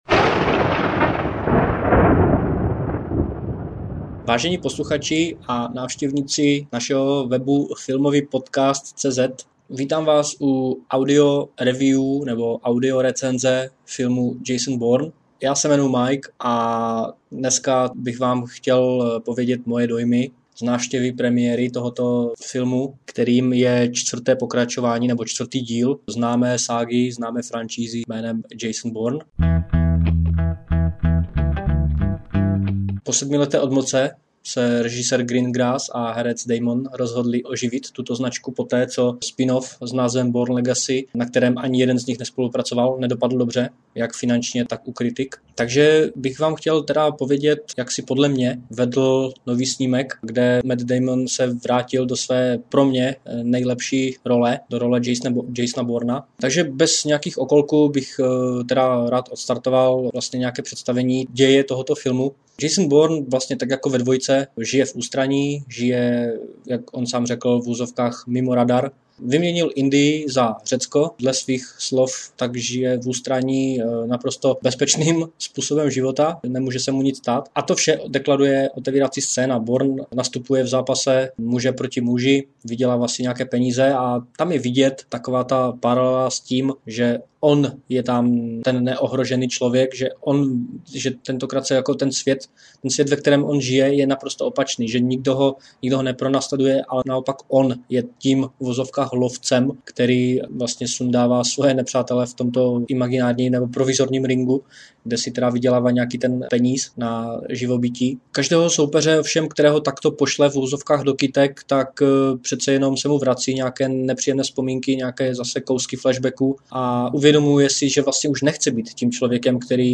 Přinášíme Vám audio recenzi nového filmu Jason Bourne.